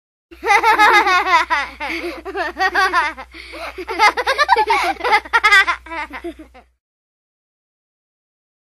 Risada Crianças Rindo
Áudio de crianças rindo. Risadas de kids.
risada-criancas.mp3